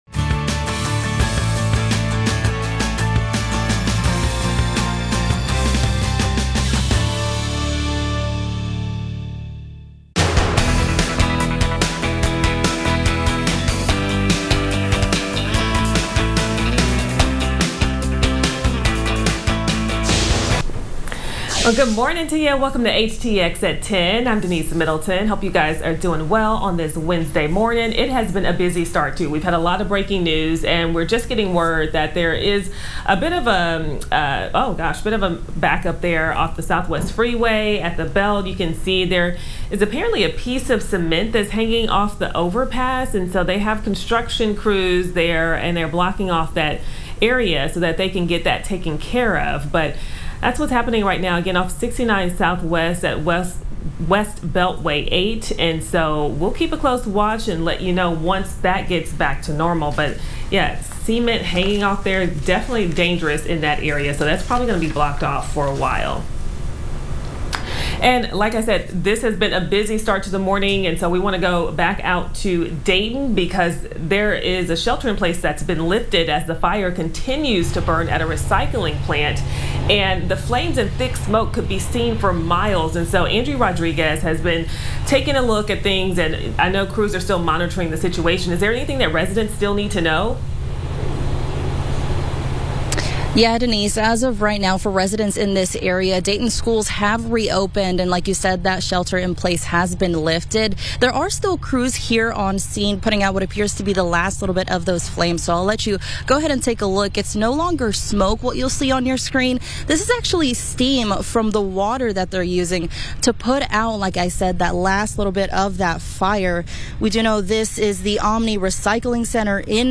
On this episode of HTX @ 10, the FOX 26 morning crew follows up on breaking news from Tuesday night and Wednesday.